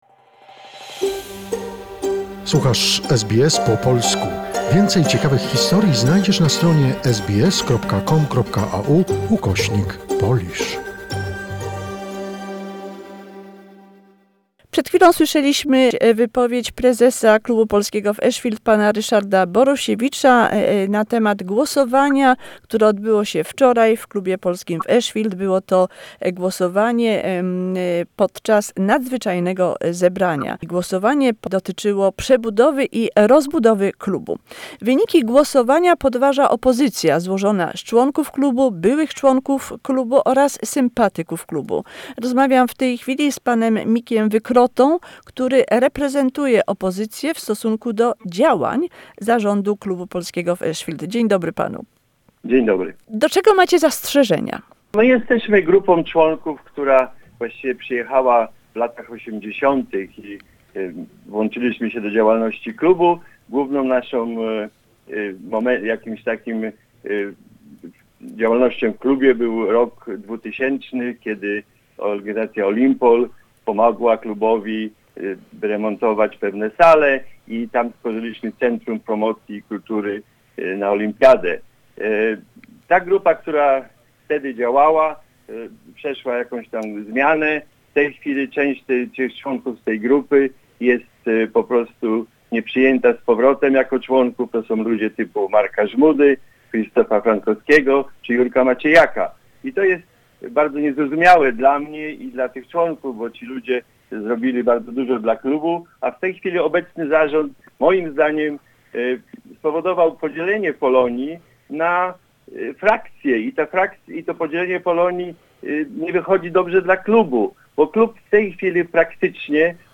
The meeting was devoted to voting on the reconstruction and expansion of the club. The opposition consisting of the members, former members of the Polish Club in Ashfield and supporters of the Club cast doubt on the results and the process of the vote. Interview